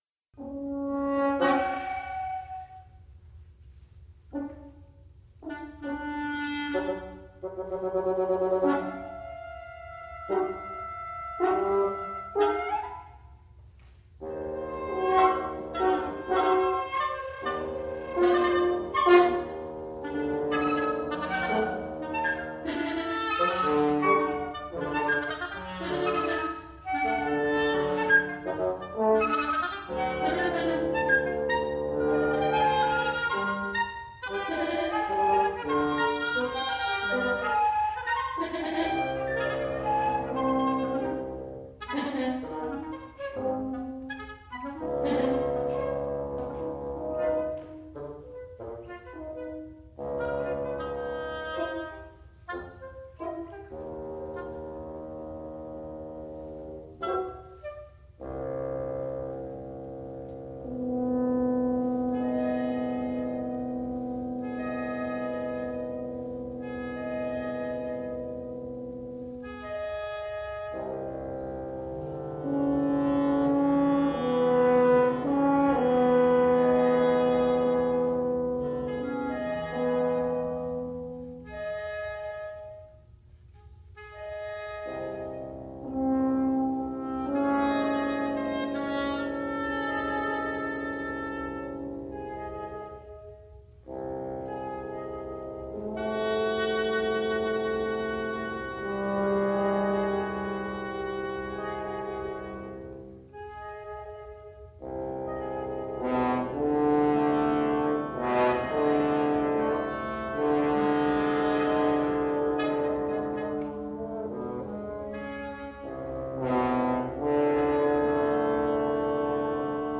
for flute, oboe, clarinet, horn, and bassoon
The musical material of the initial bassoon solo is the basis for the entire melodic and harmonic material of the piece. The work transforms through various textures, densities, pitch spaces, and time structures.